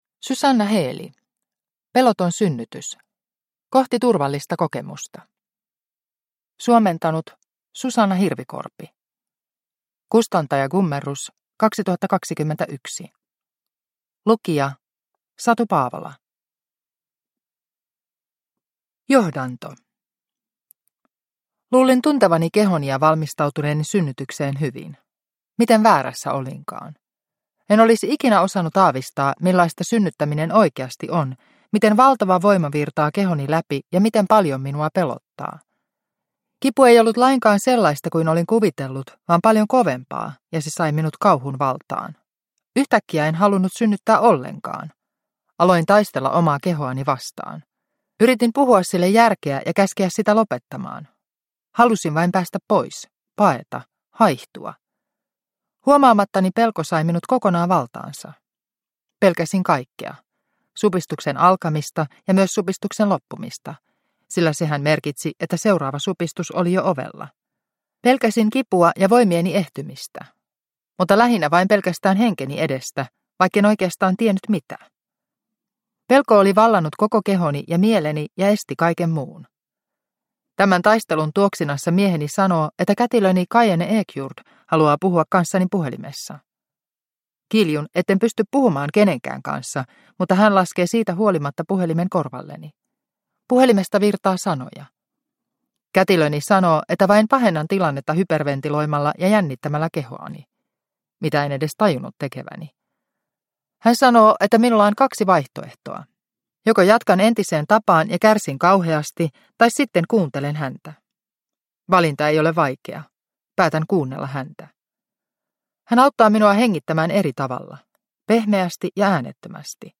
Peloton synnytys – Ljudbok – Laddas ner